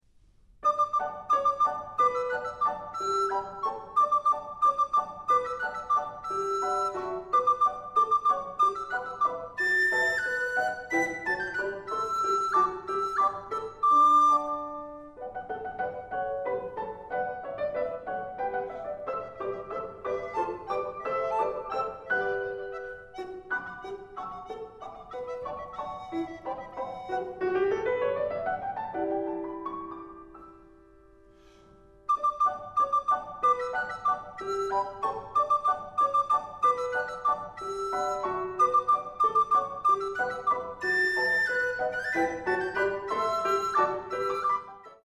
Obsazení: Flöte und Klavier